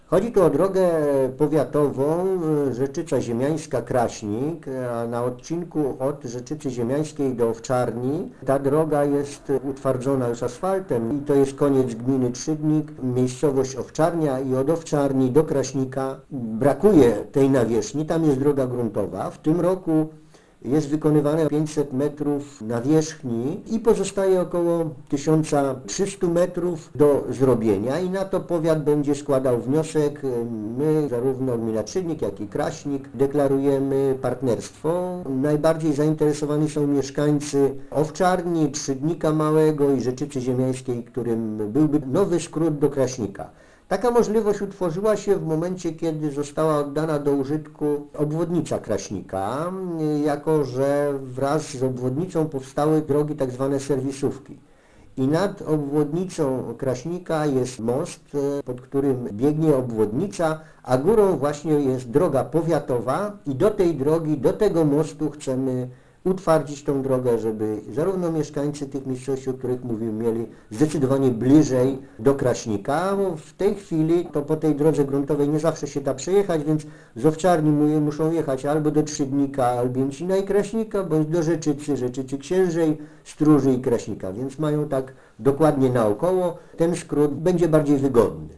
Trasa Wola Trzydnicka - Agatówka to nie jedyna "schetynówka", która w przyszłym roku może być realizowana na terenie gminy Trzydnik Duży. Podczas czwartkowej sesji Rada Gminy zgodziła się też na poparcie wniosku, który chce złożyć powiat kraśnicki - informuje wójt Kwiecień: